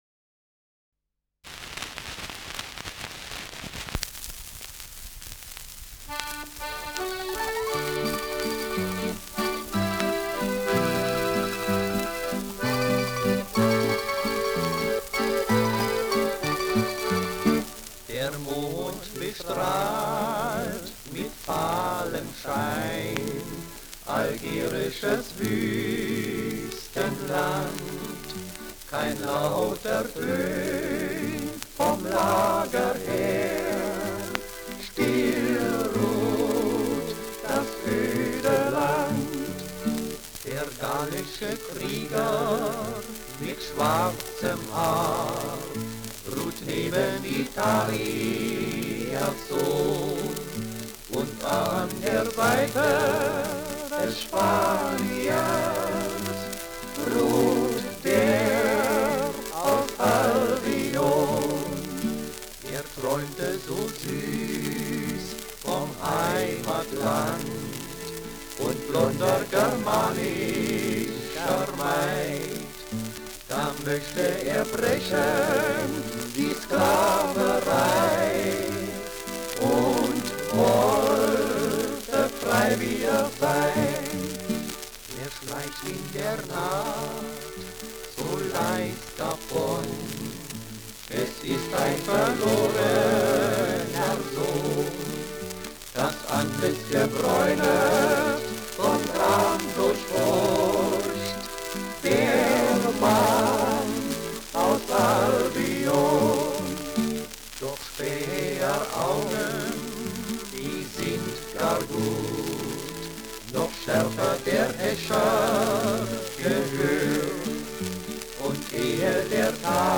Schellackplatte
Stärkeres Grundrauschen : Gelegentlich leichtes bis stärkeres Knacken : Zunehmendes Rauschen im Verlauf
Die vier Richters (Interpretation)